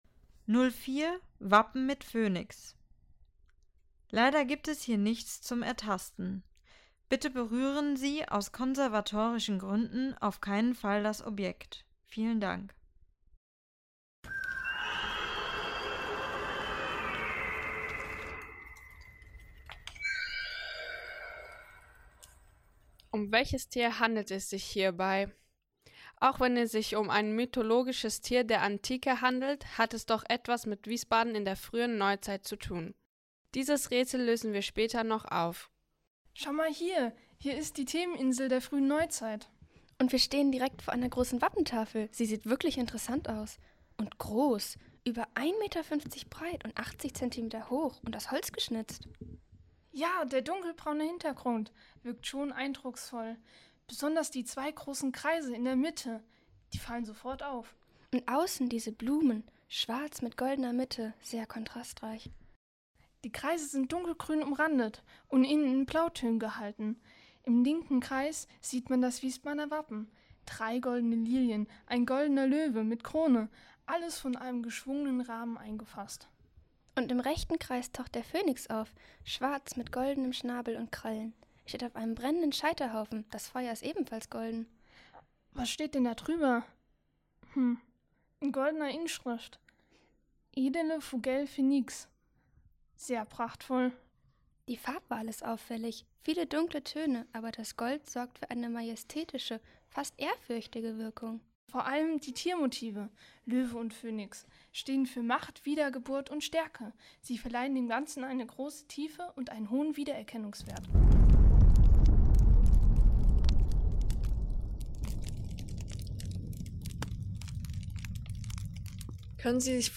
Audioguide - Station 4 Wappentafel mit Phönix-Darstellung